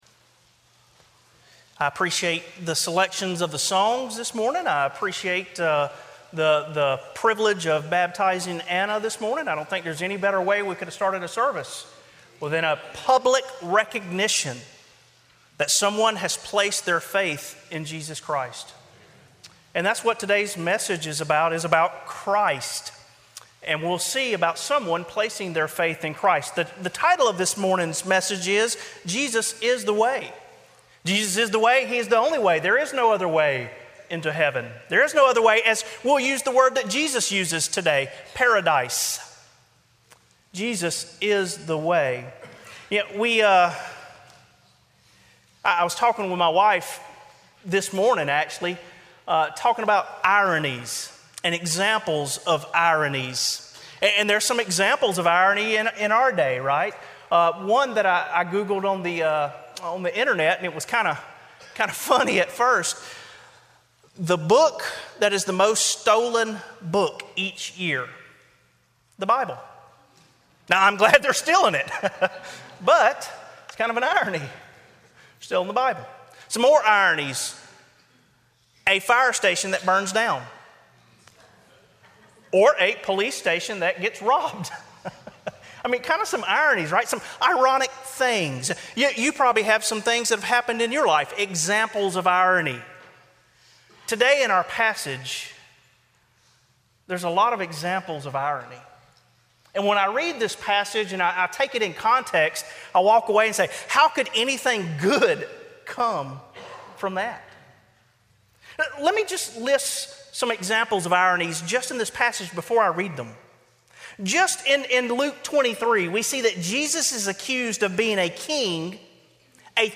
Sermon Audios/Videos - Tar Landing Baptist Church
Evening Worship1 John 1:5-2:1-6